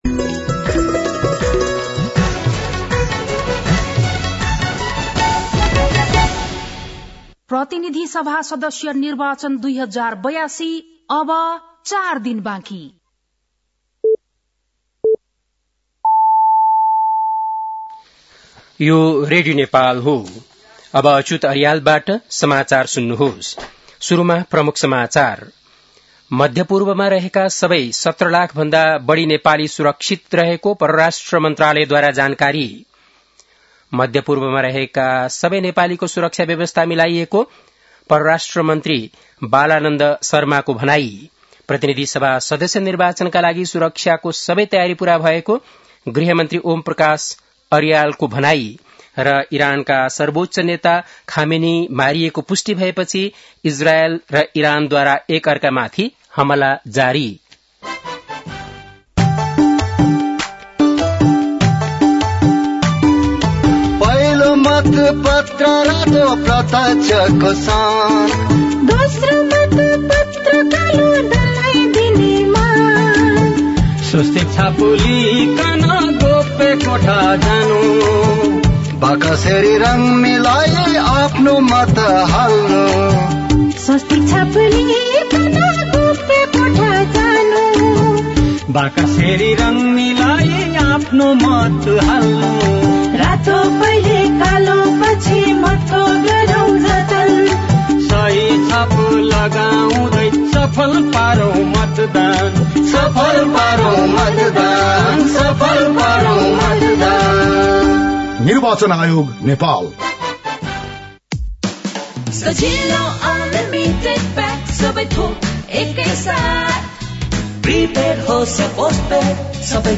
बेलुकी ७ बजेको नेपाली समाचार : १७ फागुन , २०८२
7-pm-nepali-news-11-17.mp3